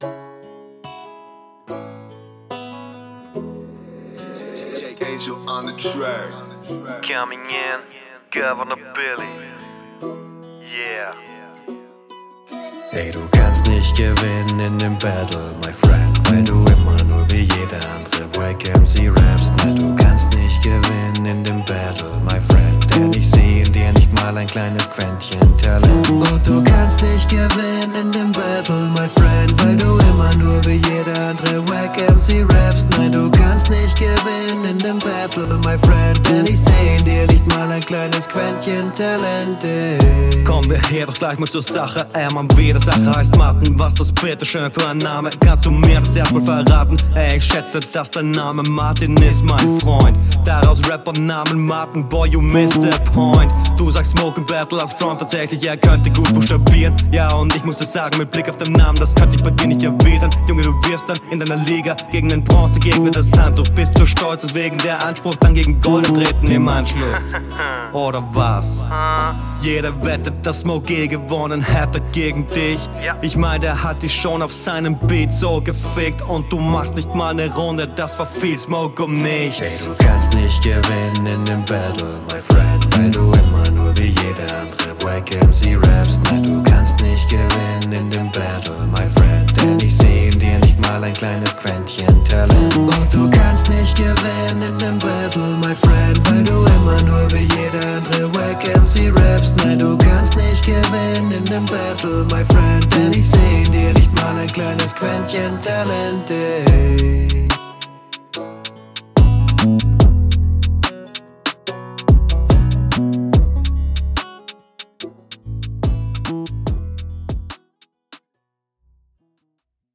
Ich mag Singsang-Rap, gute Hook.